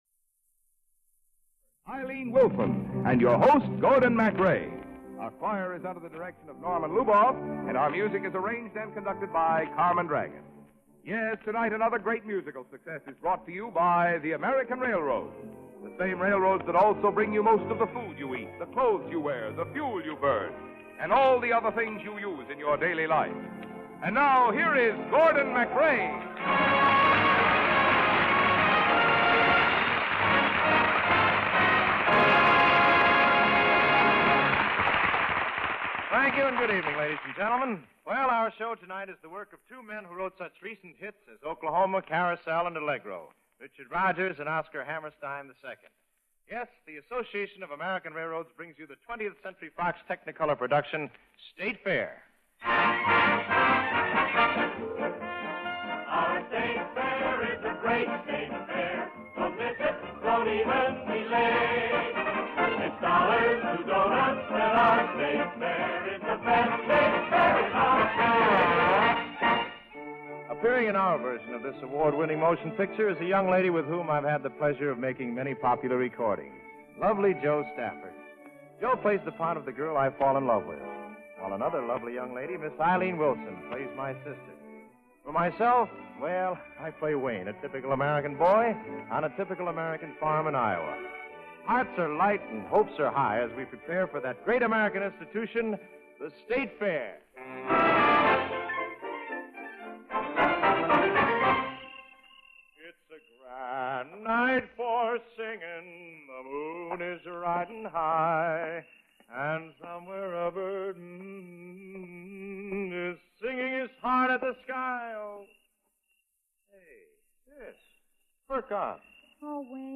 musical dramas and comedies